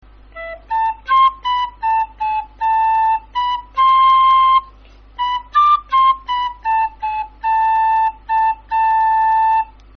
Награш: сопілка (mp3)